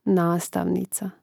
nástāvnica nastavnica